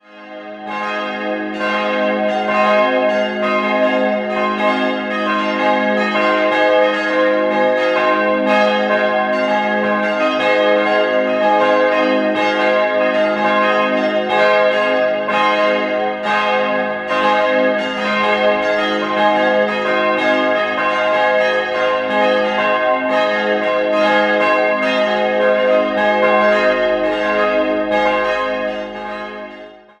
Die heutige, preisgekrönte Nikolauskirche wurde 1991 eingeweiht. 4-stimmiges Geläut: as'-c''-es''-f'' D ie kleine Glocke stammt noch aus der frühen Nachkriegszeit und hing bereits im alten Gotteshaus. Die drei anderen wurden 1991 im Zuge des Kirchenneubaus gegossen.